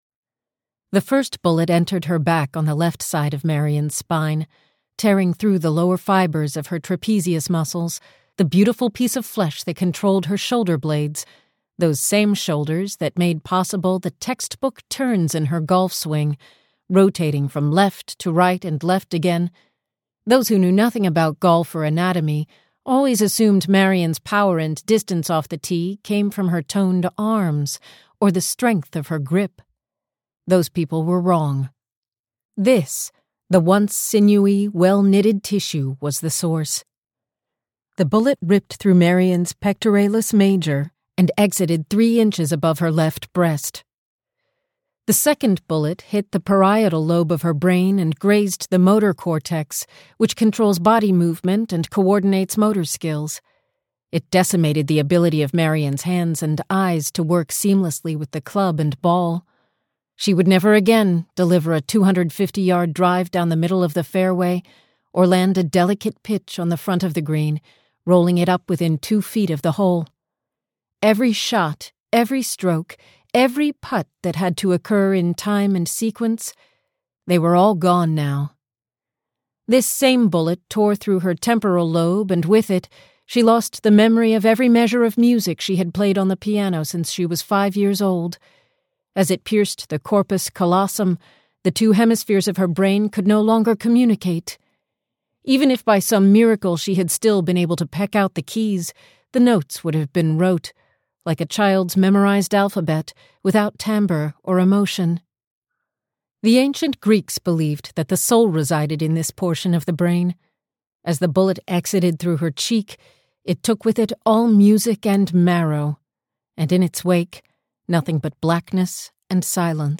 The Murder of Marion Miley - A Novel - Vibrance Press Audiobooks - Vibrance Press Audiobooks